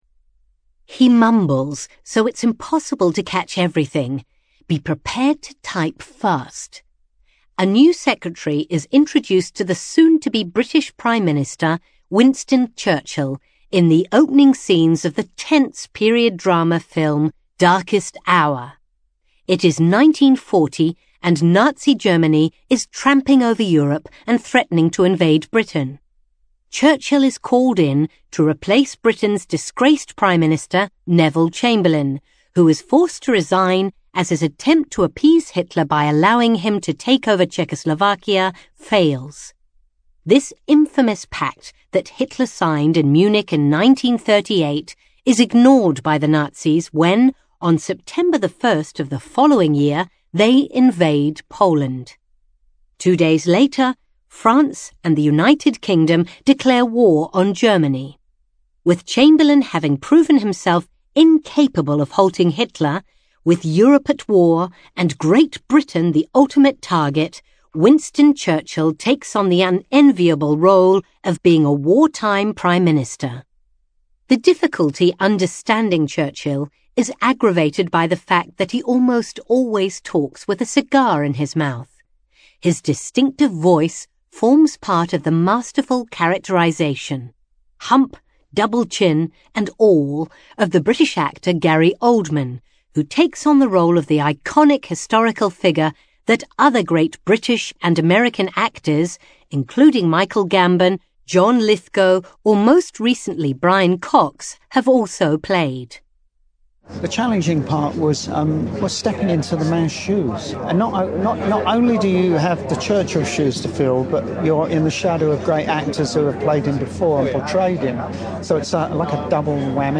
Speaker (UK accent)